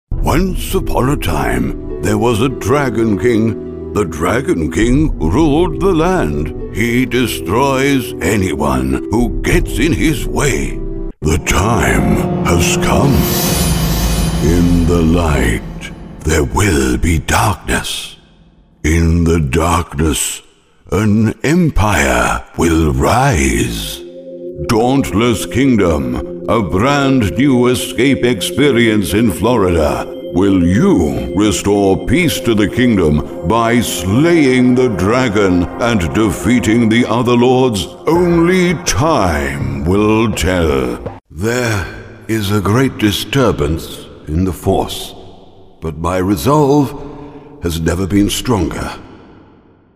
Native speakers
Engels (vk)